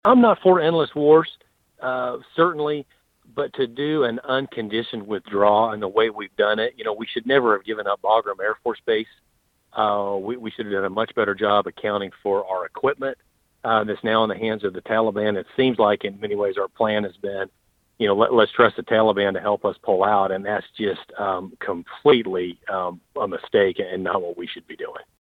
Speaking with KVOE News on Monday, Mann echoed issues outlined by other Republicans earlier this month about the withdrawal process, including the planning and what he says is our reliance on the Taliban to ensure a relatively smooth process.
3184-mann-reliance-on-the-taliban.mp3